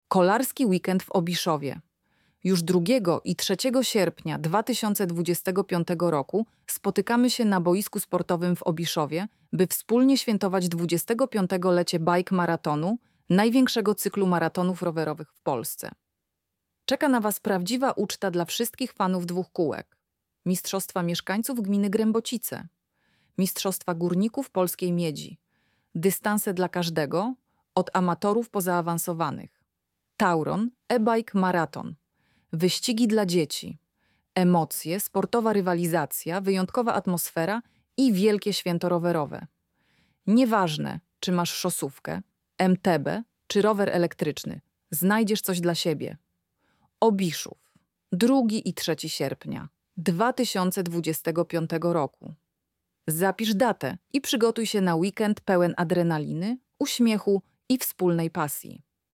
lektor-kolarski-weekend1.mp3